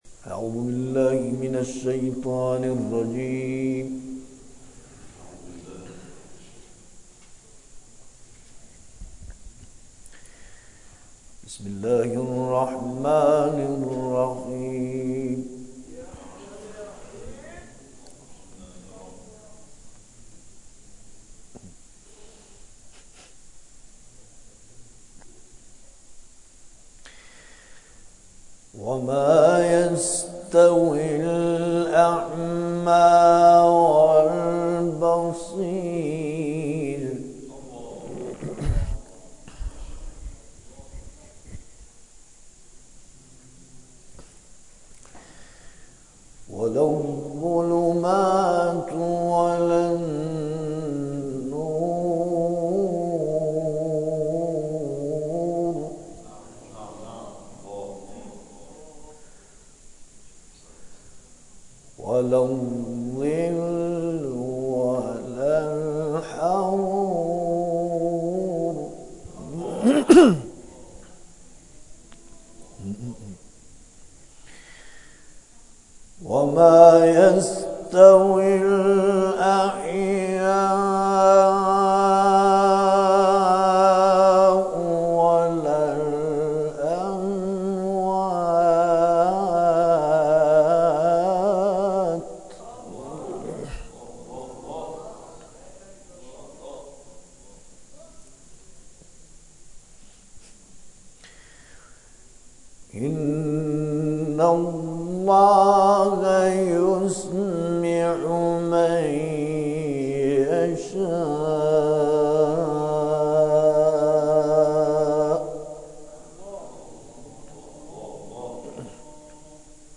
شب گذشته با حضور قاریان ممتاز در آستان امامزاده پنج تن لویزان برگزار شد.
به تلاوت آیاتی از کلام الله مجید پرداختند که در ادامه ارائه می‌شود.